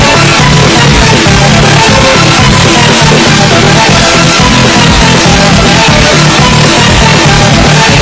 sans ear rape again
doot-doot-doot_tHfXnw1.mp3